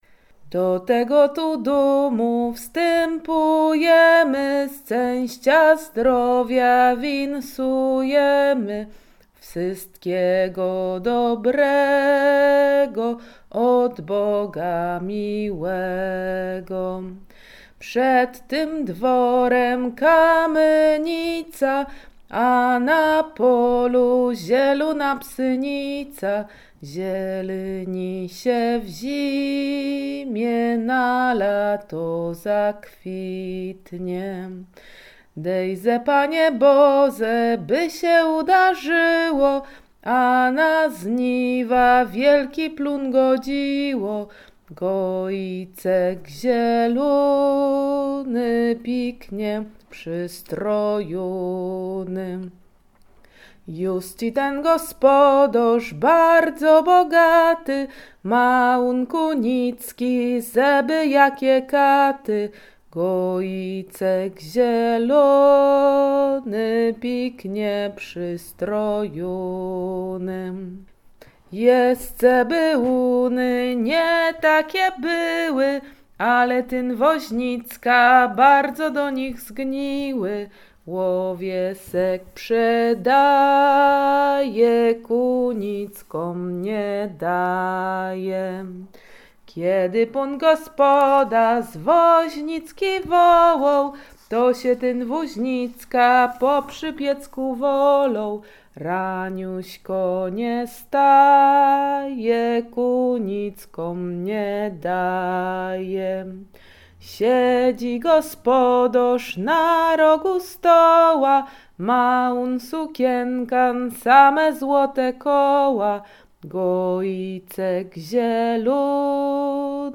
Dolny Śląsk
kolędy życzące wiosna wiosenne gaik maik kolędowanie wiosenne